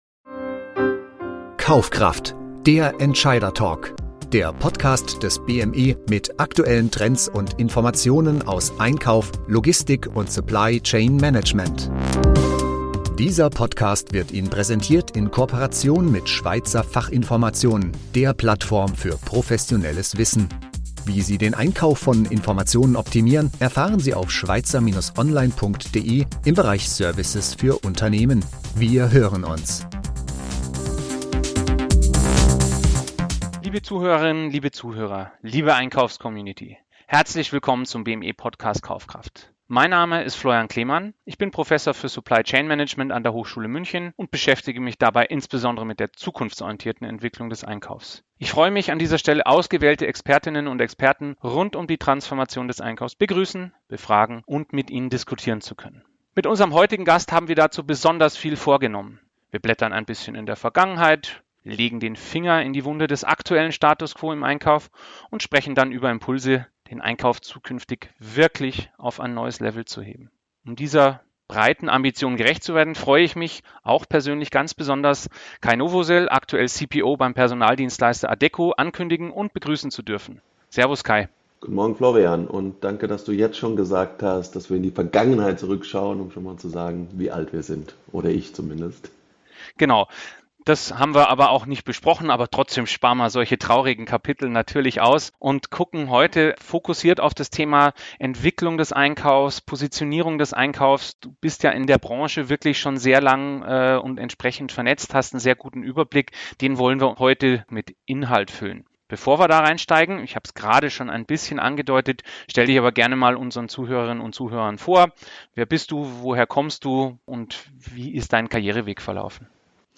Eine lebendige Diskussion über die Vorzüge von Spezialisten im Einkaufsteam.